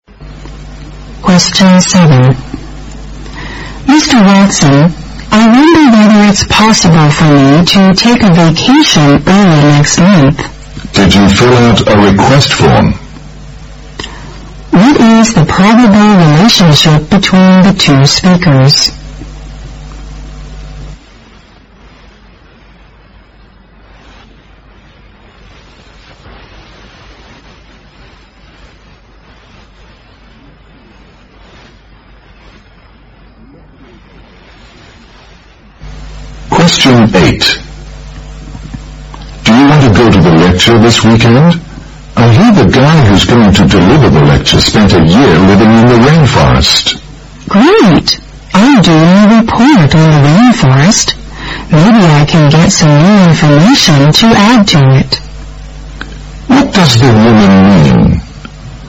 在线英语听力室077的听力文件下载,英语四级听力-短对话-在线英语听力室